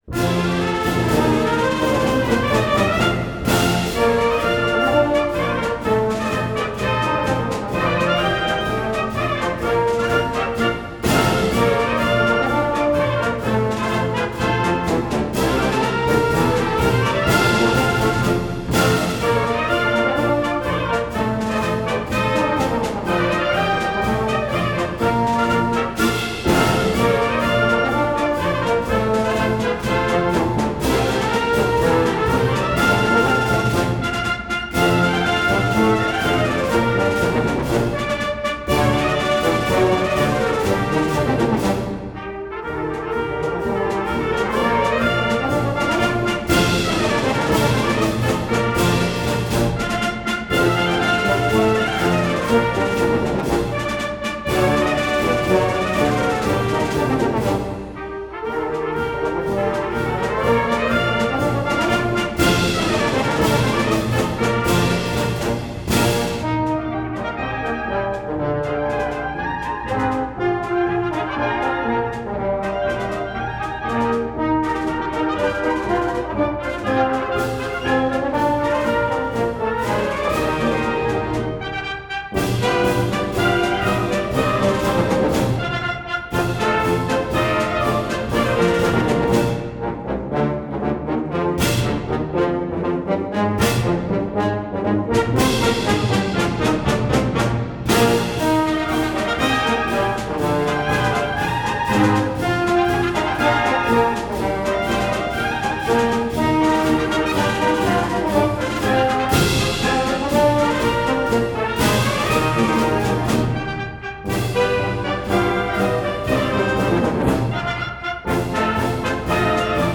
Gattung: Marsch
Besetzung: Blasorchester
„Bandwagon“-Marsch, der schnell und laut gespielt wurde